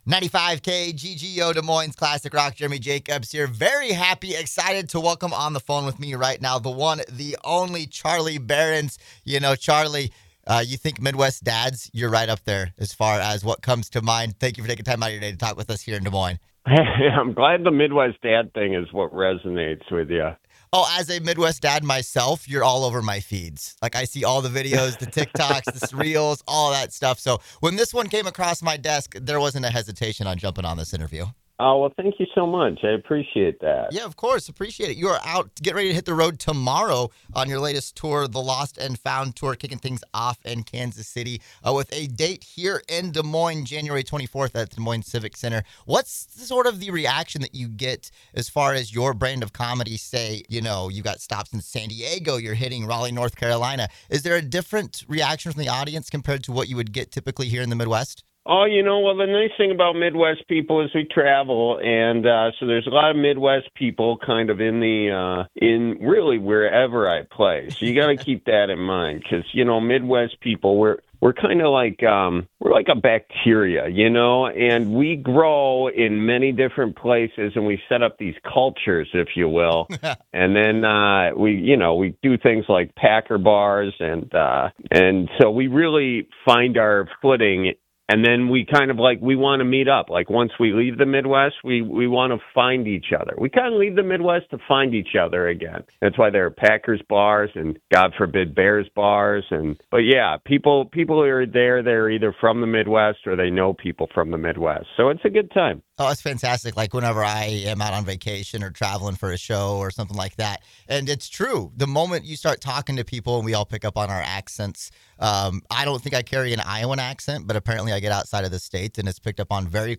Charlie Berens interview
charlie-berens-interview.mp3